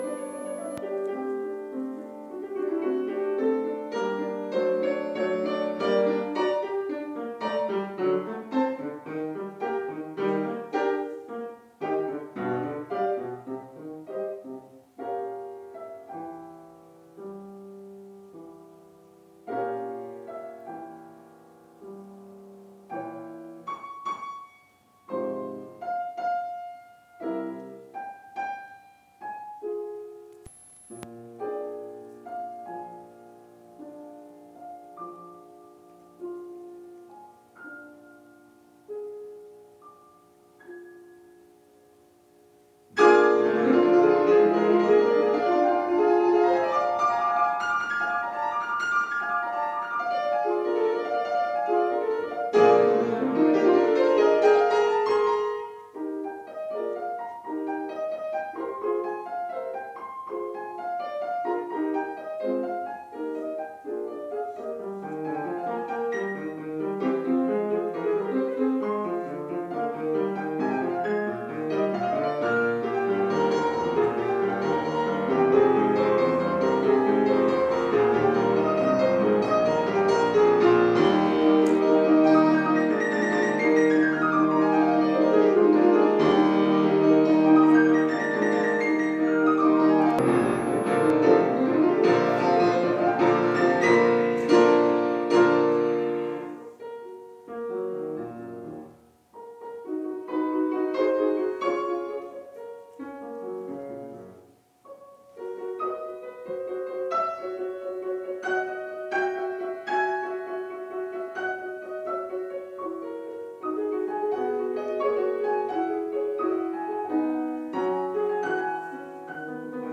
kaisersaal klavierkonzert 4